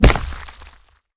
SFX击中身体喷血音效下载
SFX音效